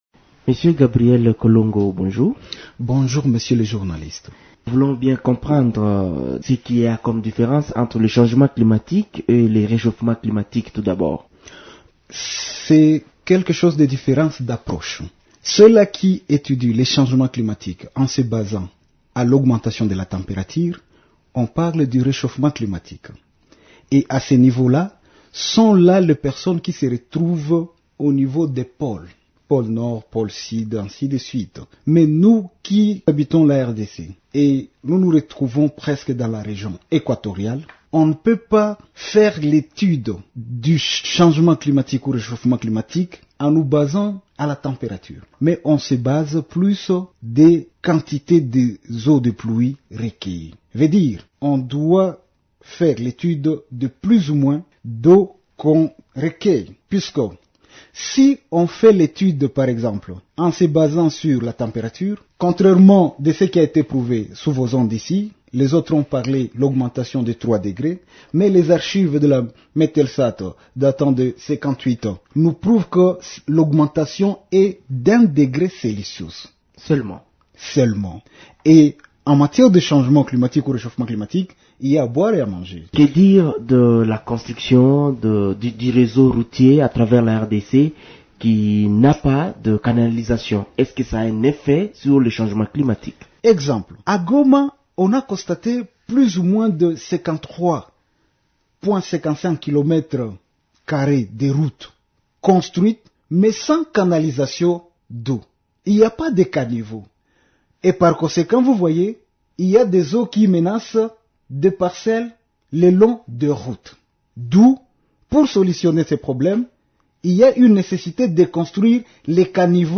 Invité de Radio Okapi ce lundi